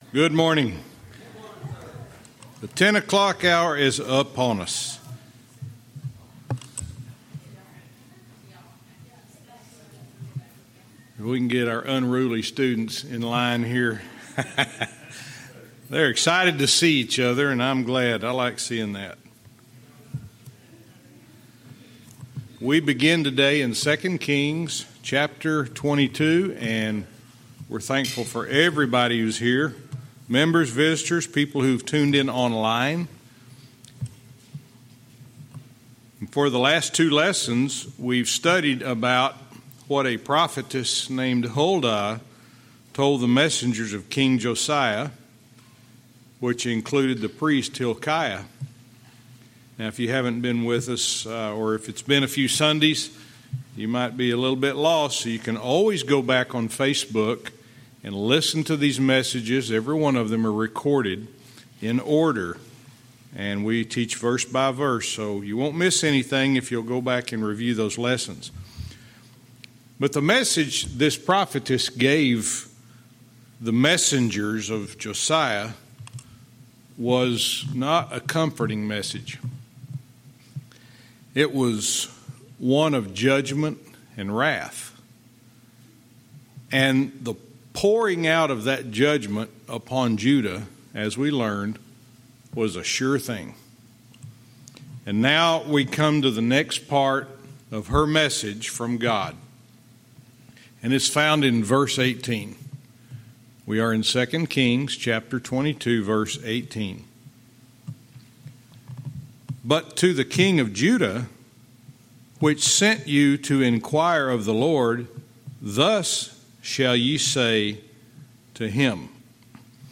Verse by verse teaching - 2 Kings 22:18-19